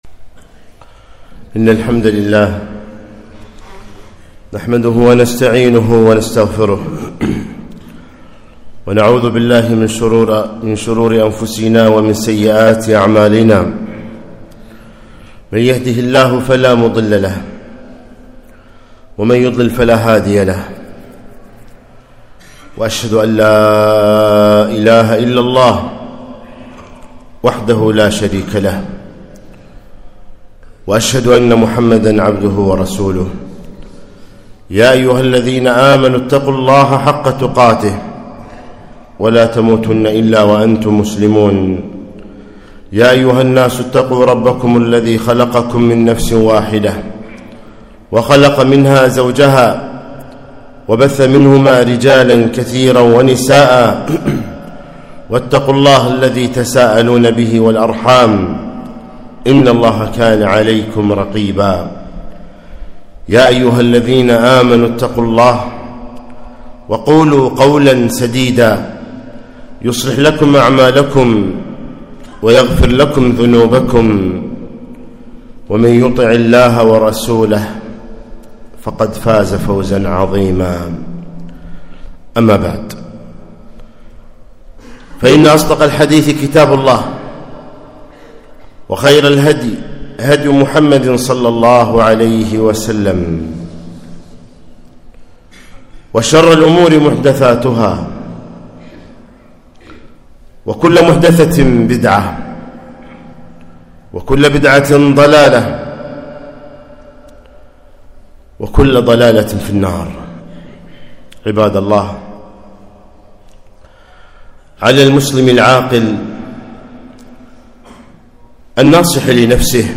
خطبة - ويحذركم الله نفسه